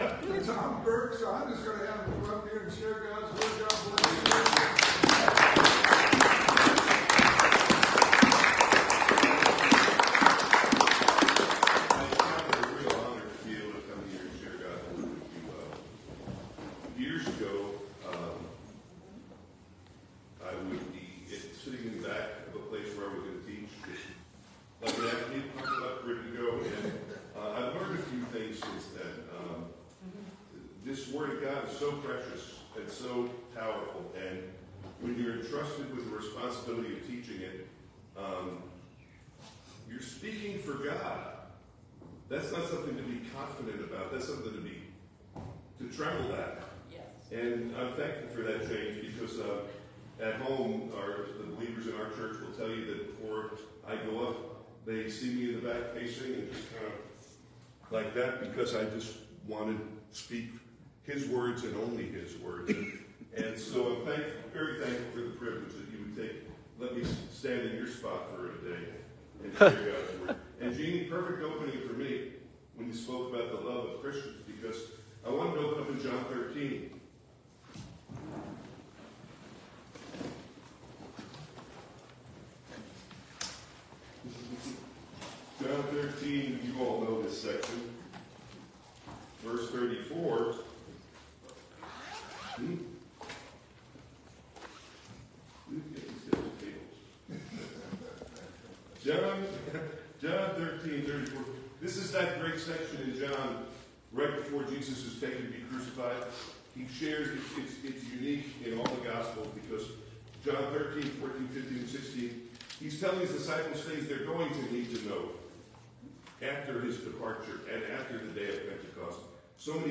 Series: Conference Call Fellowship
120 Scripture: Ephesians 4 Play the sermon Download Audio